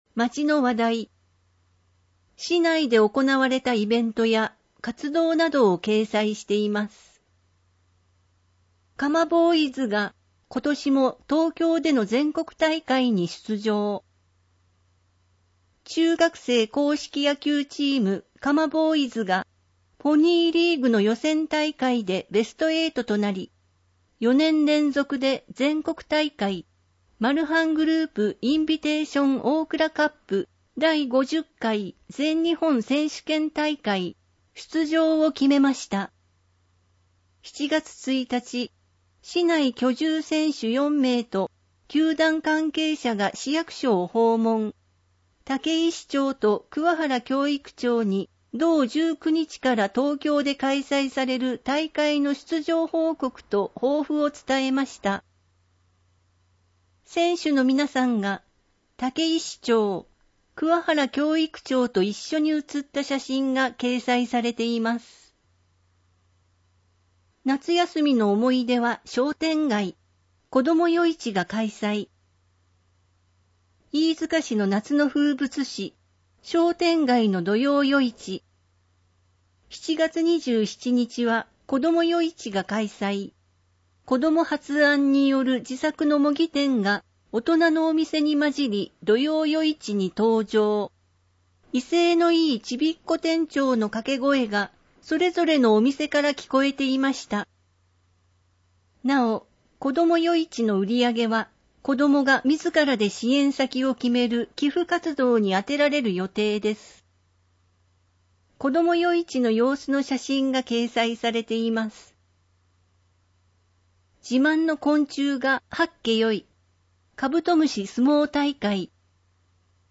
飯塚市では、ボランティア団体の皆様のご支援をいただき、広報いいづかの全記事を音声で収録した「声の市報」を発行しています。